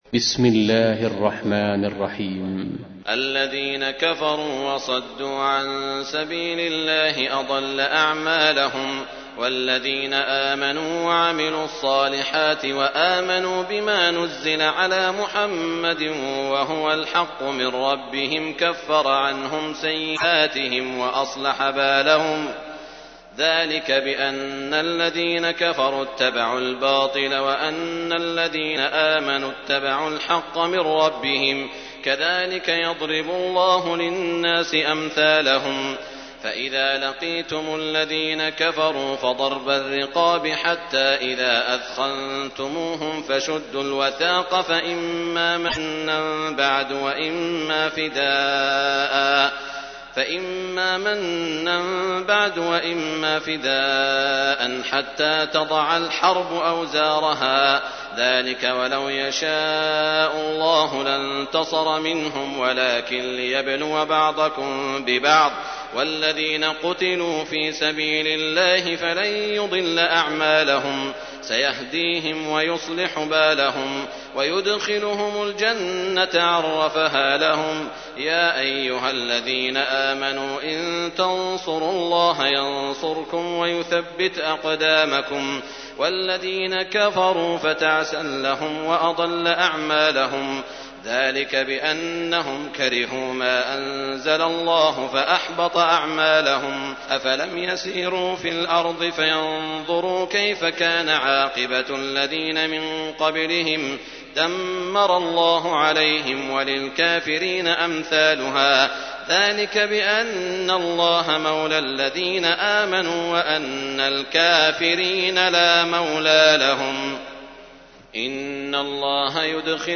تحميل : 47. سورة محمد / القارئ سعود الشريم / القرآن الكريم / موقع يا حسين